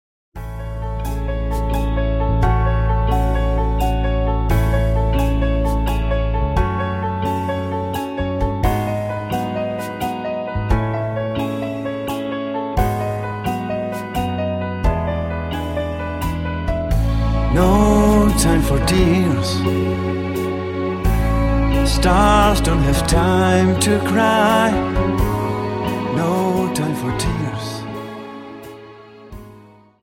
Dance: Slow Waltz Song